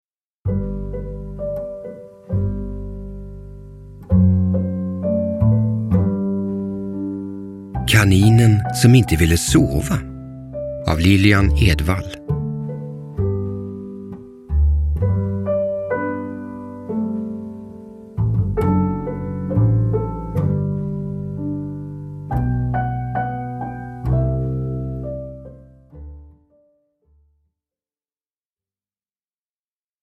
Kaninen som inte ville sova – Ljudbok – Laddas ner